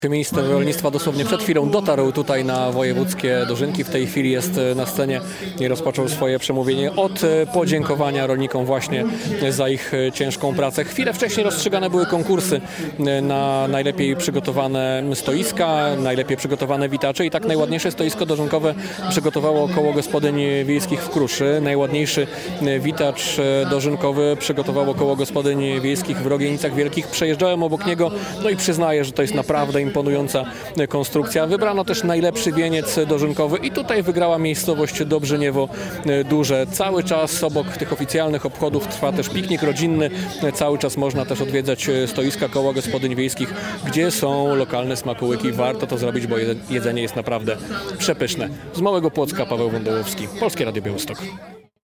W Małym Płocku trwają Dożynki Wojewódzkie - relacja